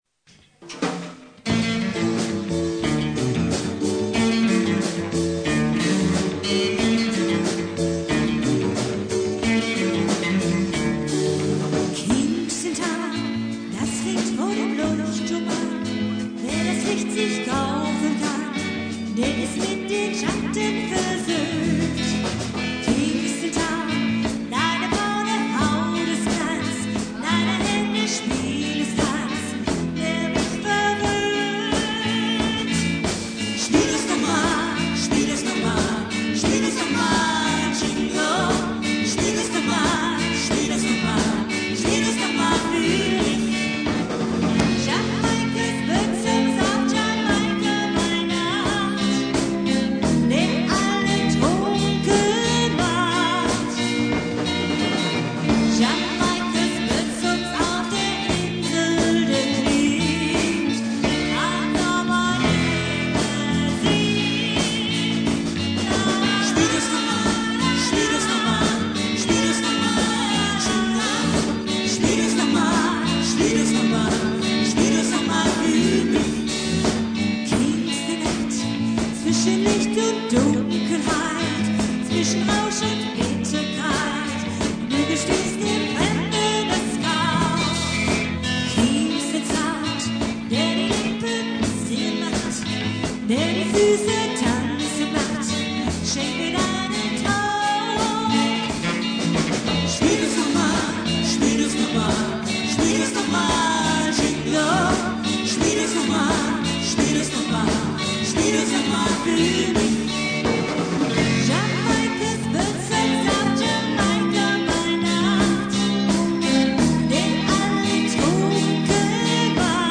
Livemitschnitt während eines Tanzabends in Wiesede/Ostfriesland.
Gitarre und Gesang
Keyboard und Gesang
Schlagzeug und Gesang
Bass und Gesang
Die Aufnahmen haben im Laufe der Jahrzehnte doch schon etwas gelitten.